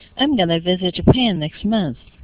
There are commonly used supra-segmental features in everyday American English conversation, which makes the target language fast and fluent.
Going to             gonna
2. n/ er/ fer